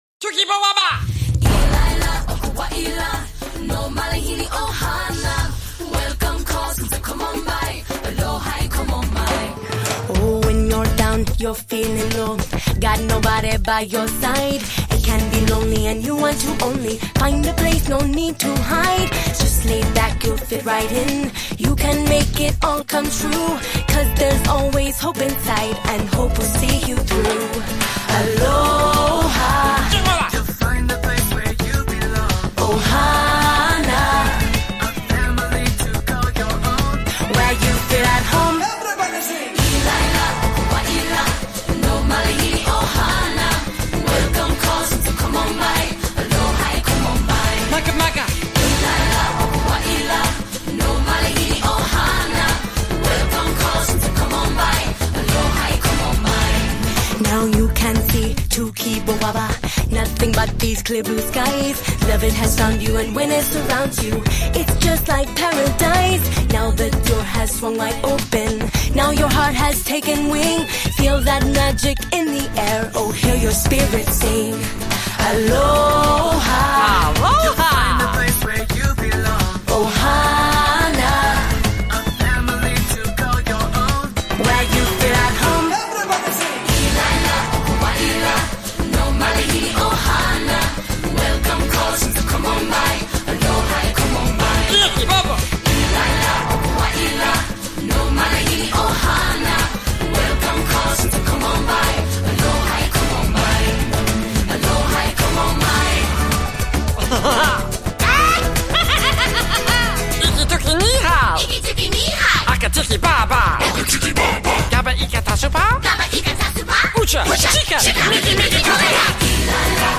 Сразу несколько голосов поют в унисон.